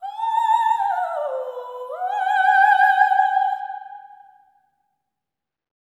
OPERATIC08-R.wav